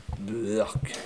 beurk_01.wav